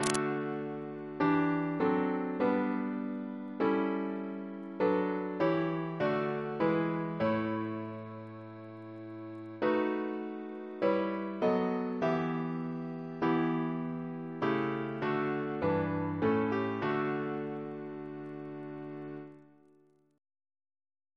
Double chant in D Composer: Walter G. Alcock (1861-1947), Professor of Organ, RCM Reference psalters: RSCM: 1